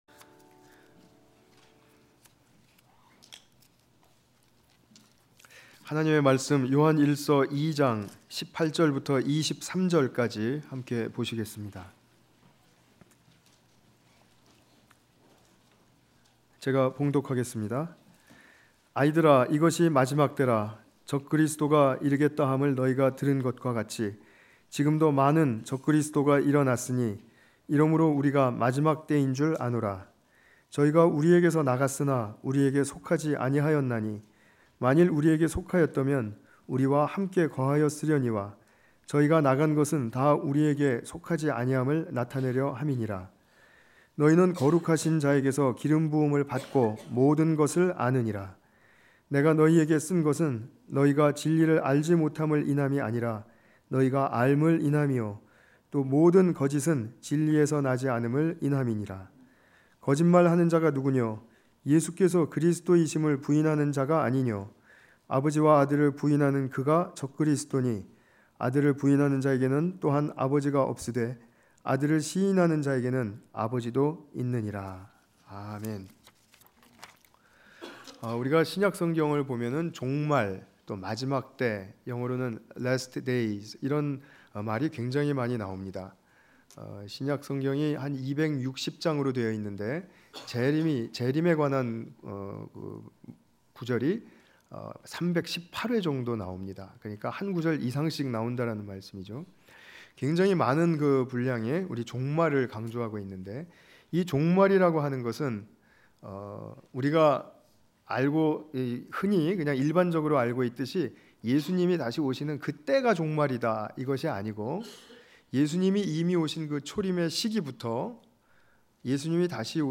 요한일서 2장 18-23절 관련 Tagged with 주일예배